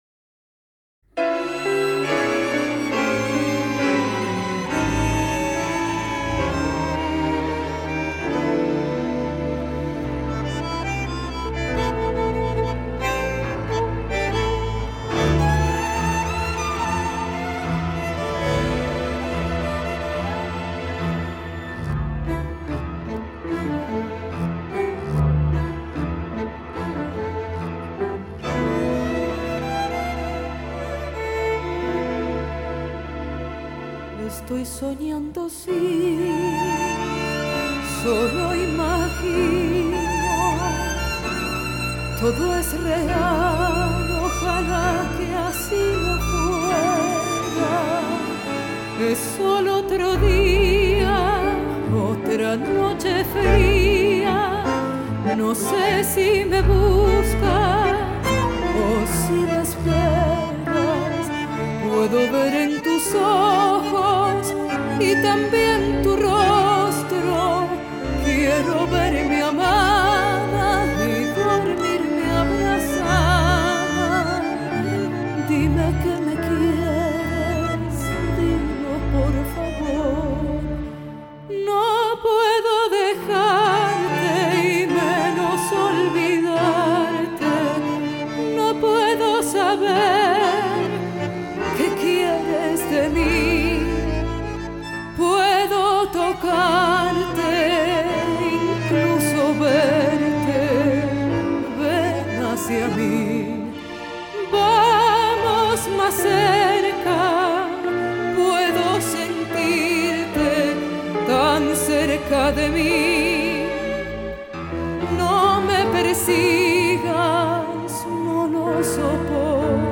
печальная баллада с испанским вокалом